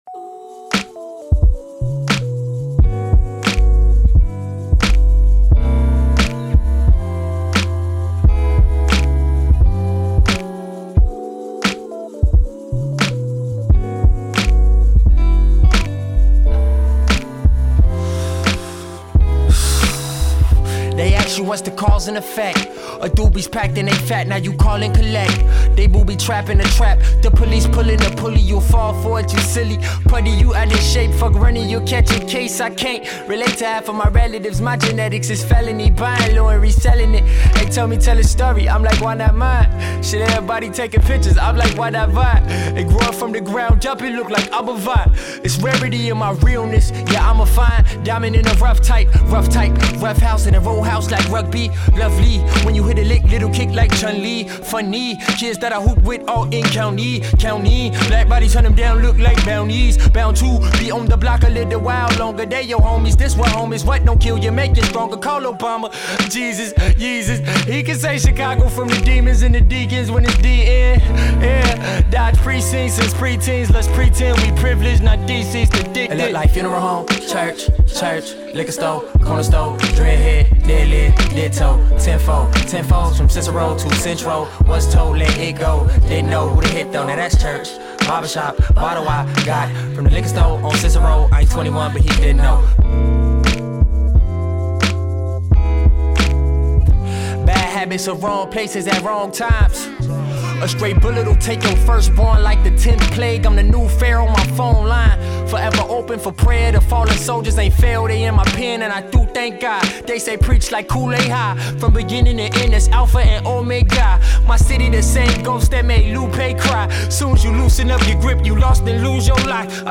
With ethereal production